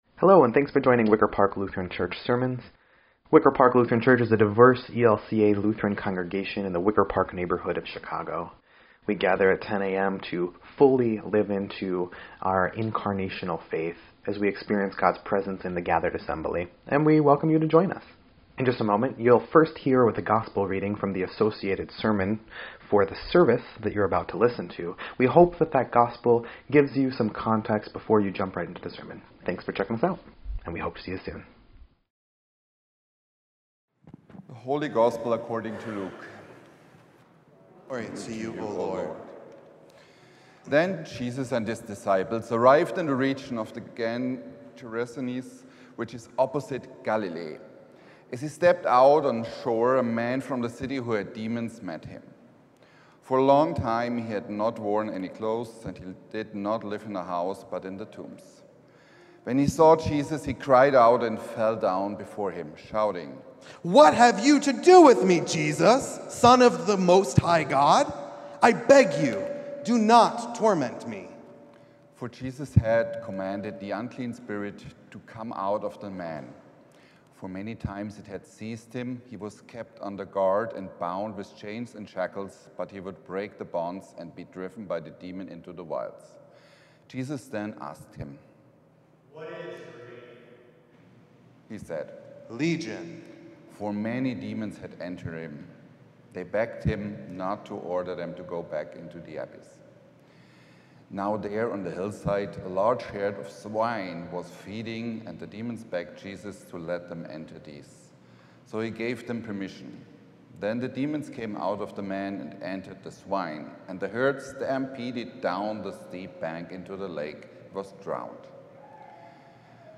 6.22.25-Sermon_EDIT.mp3